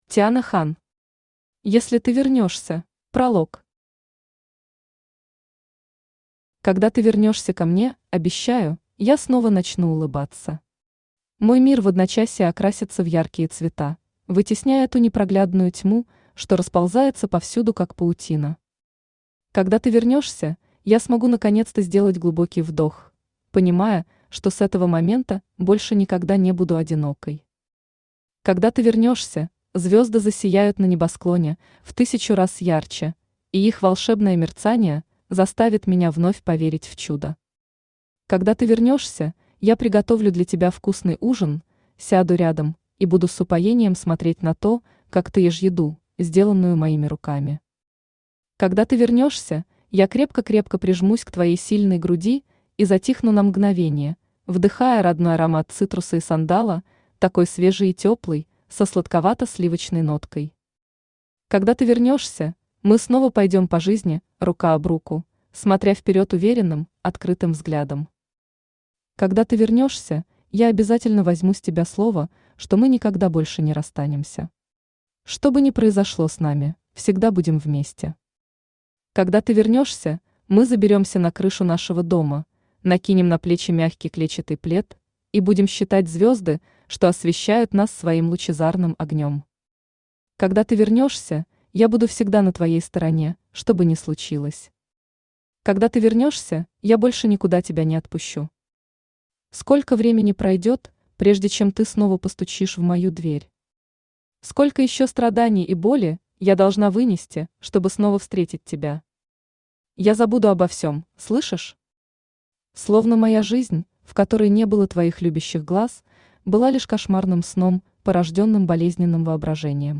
Аудиокнига Если ты вернёшься…